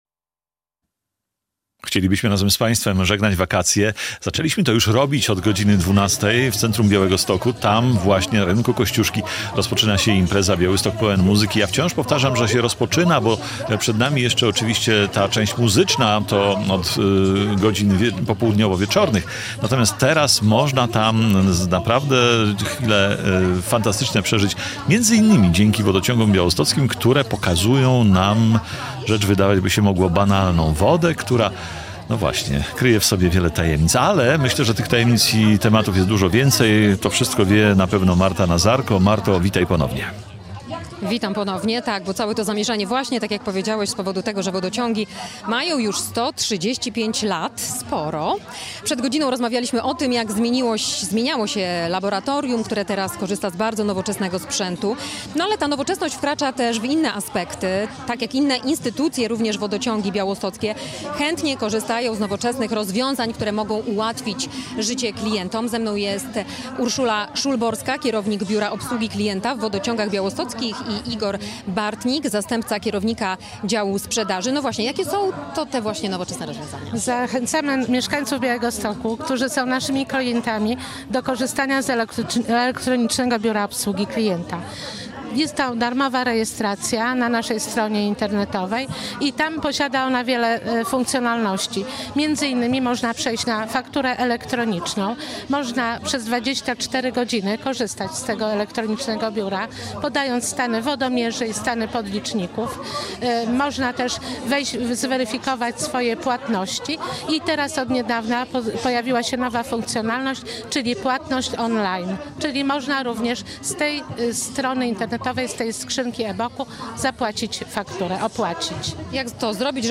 Jest też nasze plenerowe studio - nadajemy z Rynku Kościuszki od 12:00 do 17:00.